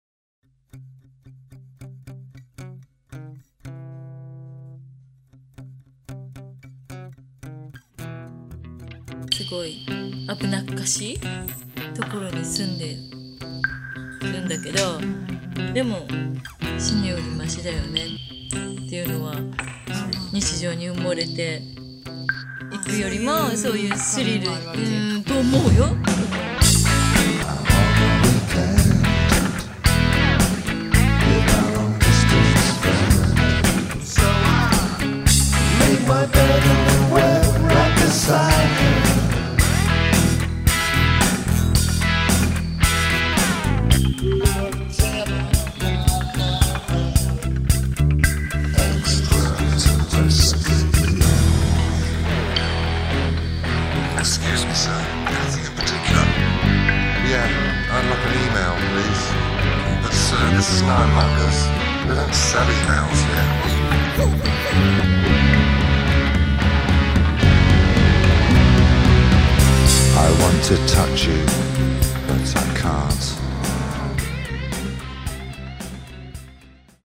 Asian-tinged psychedelic punk